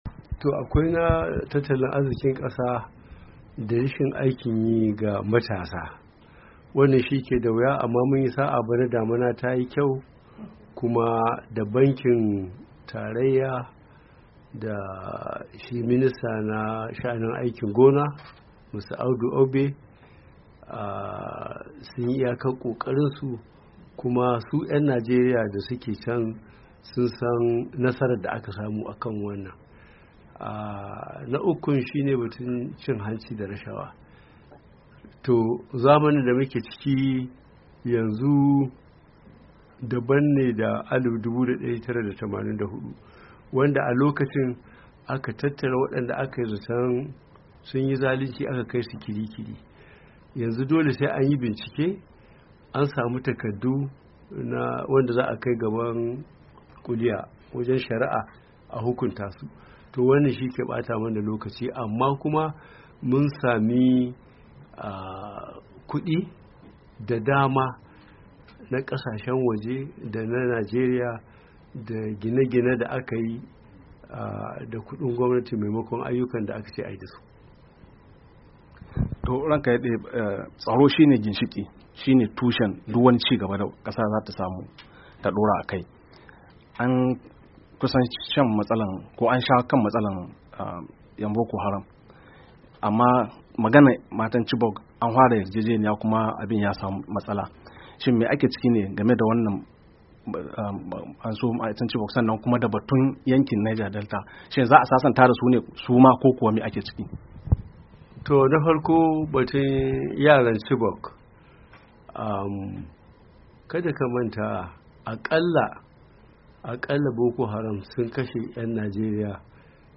WASHINGTON, DC —